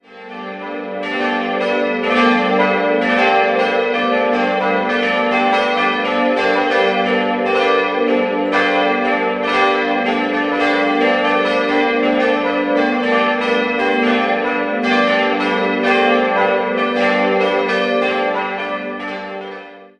Ihr konventionellesÄußeres fügt sich gut ins Ortsbild ein. 4-stimmiges Geläut: g'-a'-h'-d'' Die Glocken wurden 1961 von der Gießerei Bachert in Karlsruhe gegossen.